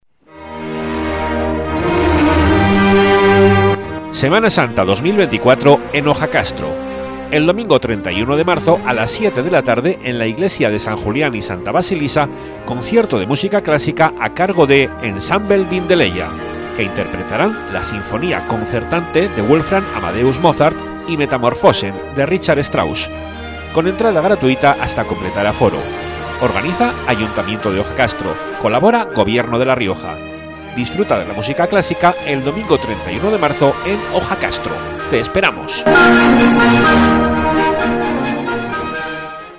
Anuncio en la radio del concierto de música clásica